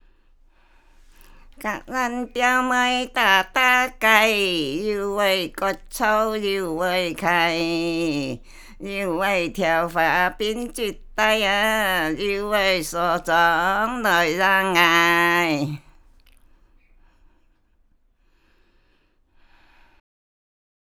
繼3年前〈傳統圍頭．客家歌謠與昔日鄉村生活誌〉的延續，將推出新的客家及圍頭傳統歌謠的光碟。